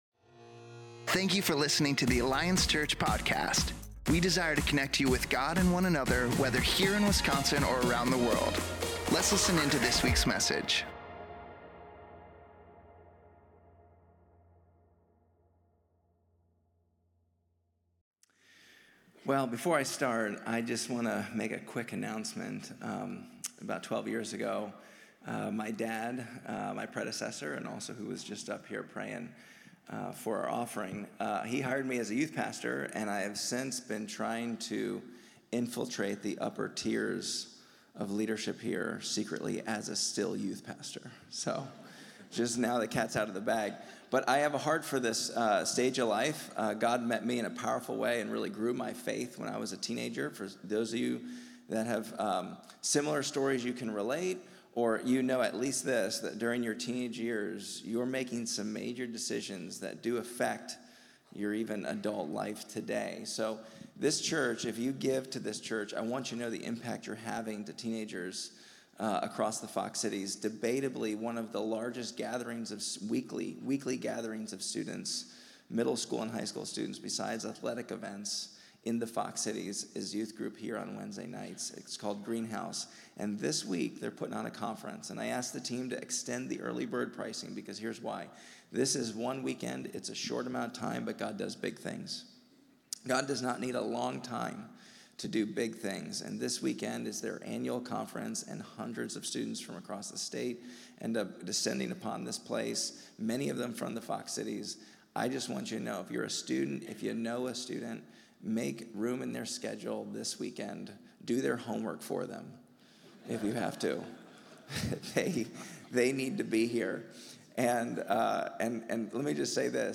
October 15th Appleton Service